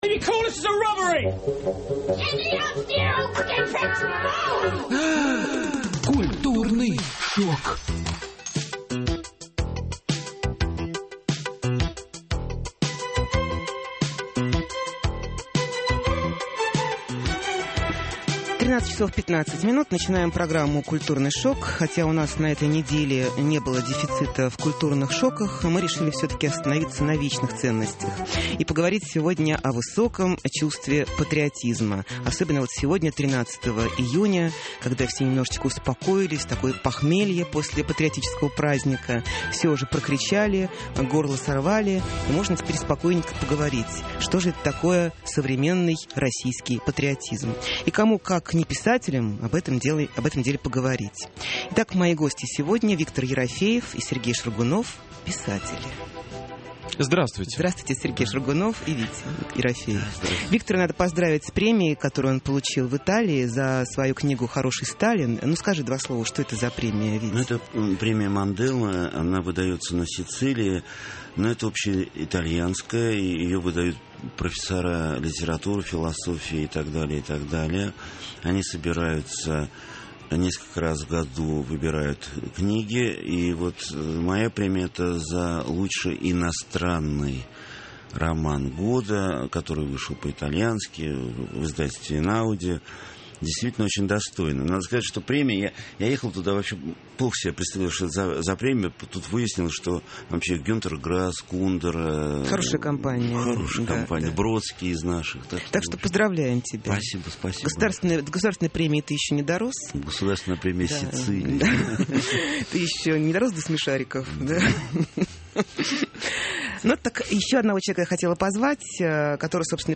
Мои гости сегодня Виктор Ерофеев и Сергей Шаргунов, писатели.